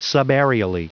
Prononciation du mot subaerially en anglais (fichier audio)
Prononciation du mot : subaerially